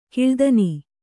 ♪ kiḷdani